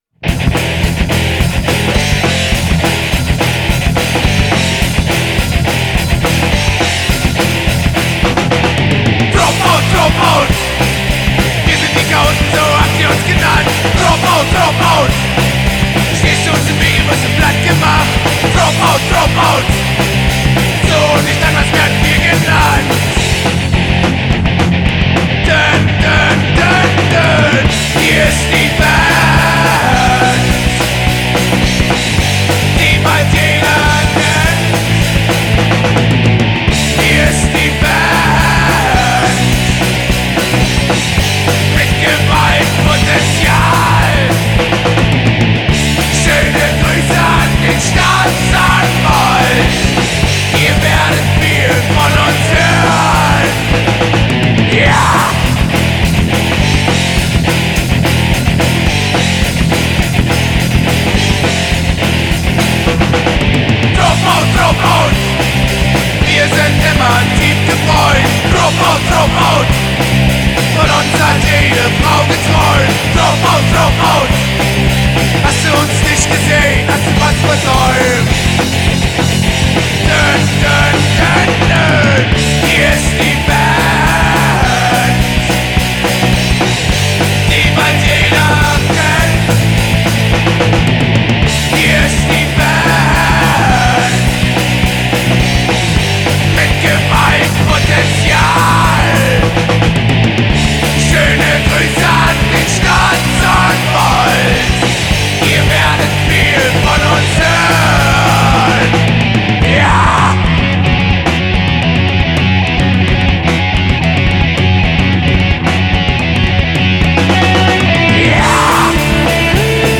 einen sehr melodiösen aber harten Sound
Streetrock mit Oi! & Punkeinflüssen und Bombast-Chören,
verpackt in knackige Ohrwürmer-Refrains!